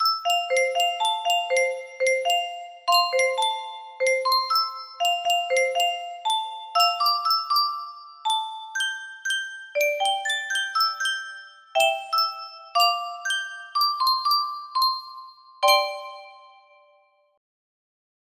Unknown Artist - Untitled music box melody
Hey! It looks like this melody can be played offline on a 20 note paper strip music box!